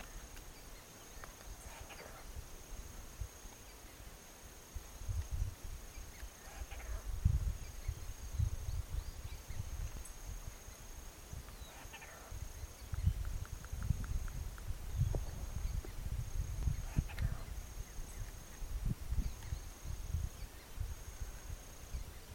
Tingazú (Piaya cayana)
Nombre en inglés: Common Squirrel Cuckoo
Fase de la vida: Adulto
Localidad o área protegida: Ruta 6 (entre Concepcion y Mburucuya)
Condición: Silvestre
Certeza: Observada, Vocalización Grabada